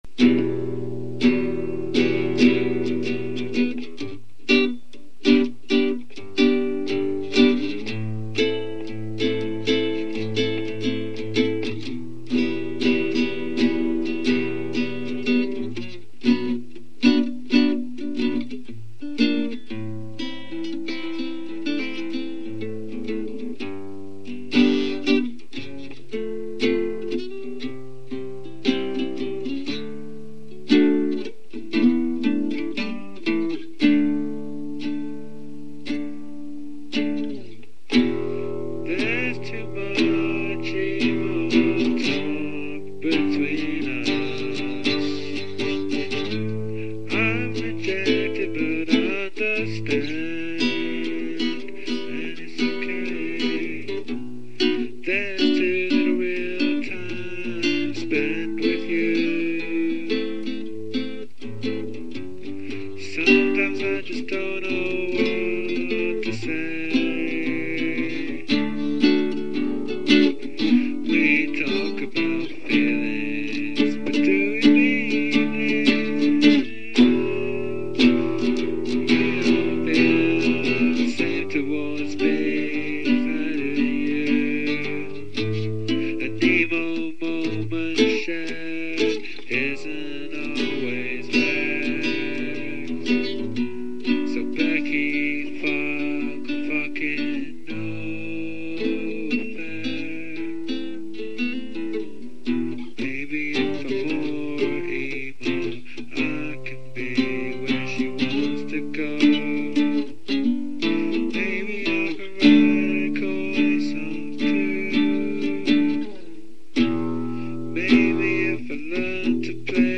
A melodic pop-rock band from Catford in South London